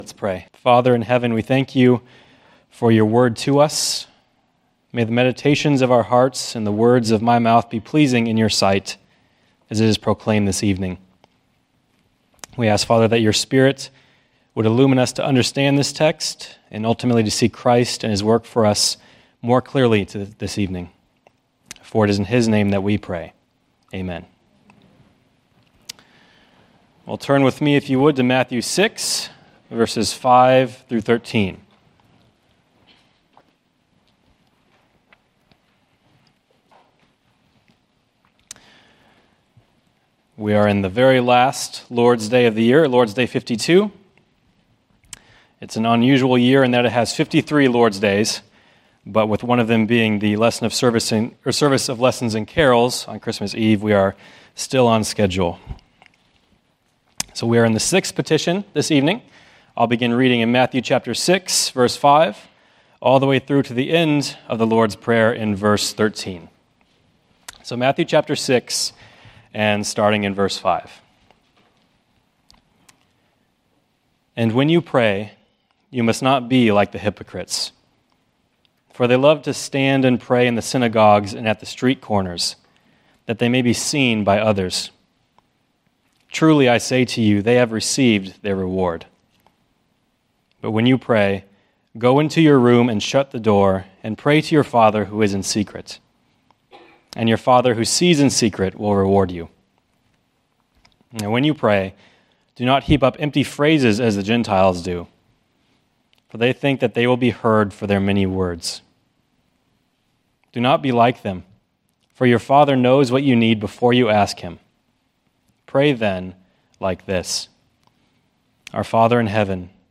Sermons — Christ United Reformed Church